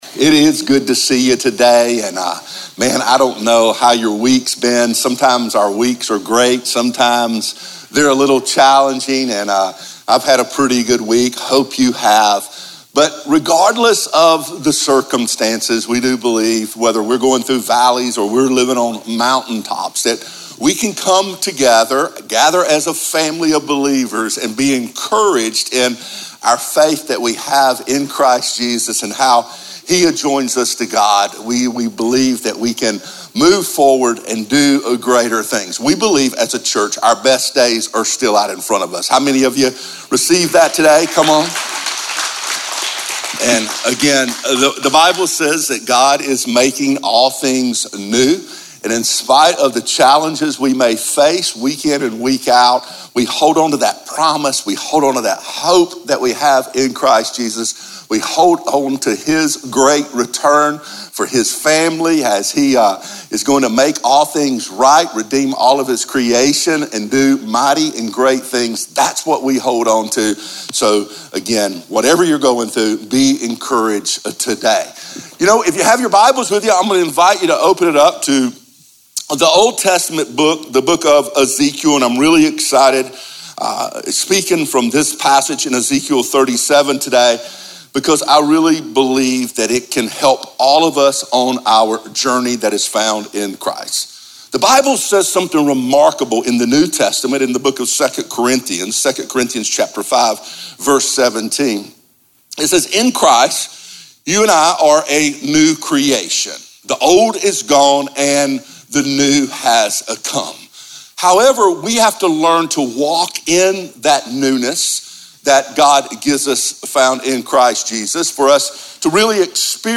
a dynamic, high-energy speaker with a heart and vision to reach the world.
He is known for his ability to captivate and challenge the audience by delivering the Word of God in a way that is relevant to our world today.